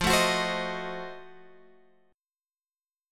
Listen to F7b9 strummed